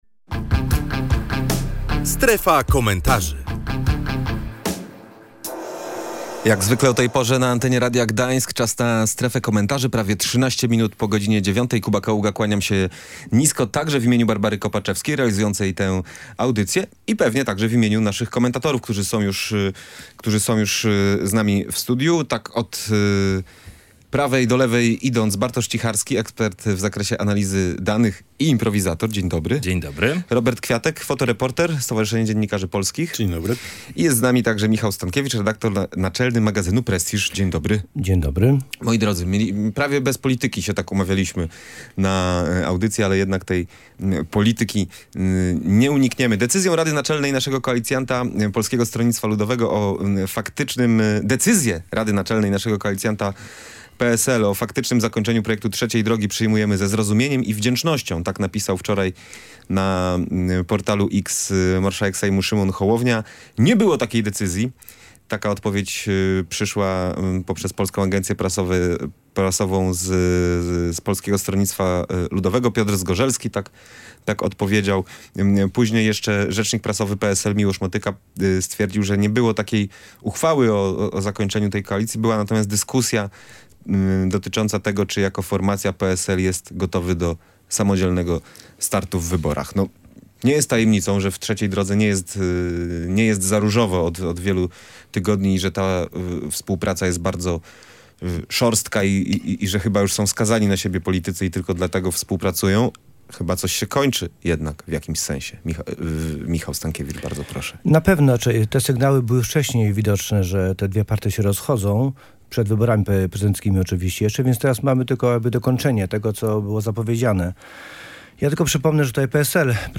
Koniec Trzeciej Drogi. Komentatorzy oceniają, co dalej z PSL i Polską 2050